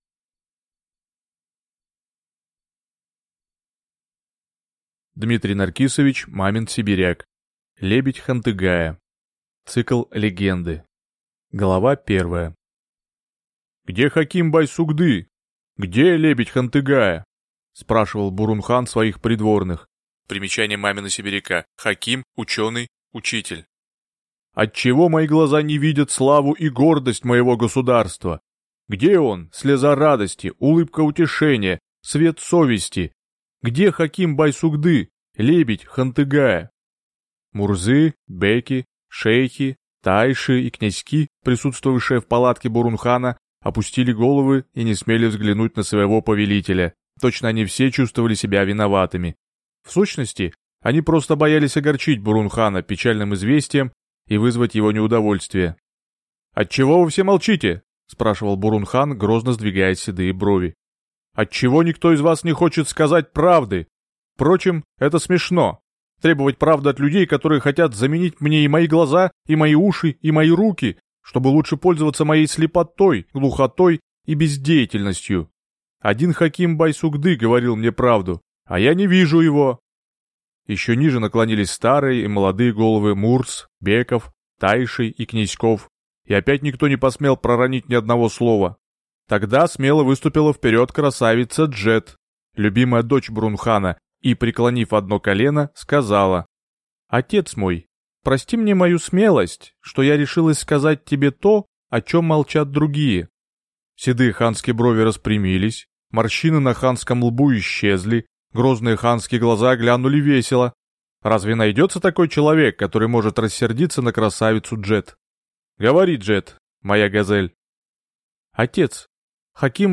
Аудиокнига Лебедь Хантыгая | Библиотека аудиокниг